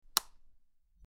Light Switch Click 04
Light_switch_click_04.mp3